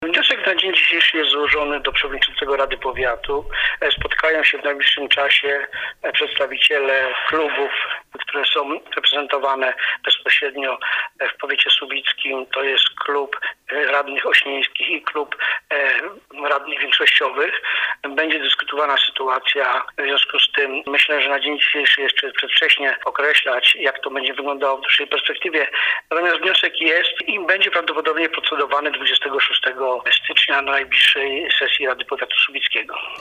W rozmowie z nami starosta Leszek Bajon potwierdził, że wniosek o odwołanie wicestarosty został złożony do rady powiatu i najprawdopodobniej będzie procedowany na najbliższej sesji rady powiatu, 26 stycznia: